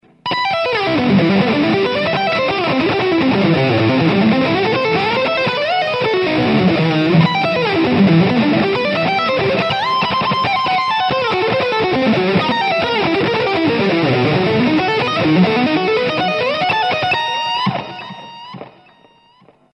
Tak aby sa nepovedalo, že dlho sa nič nedeje, pridávam jednu ukážku - taký arpeggiový nápadík.
Díky, bolo to cez ten Line6 PODxt Live.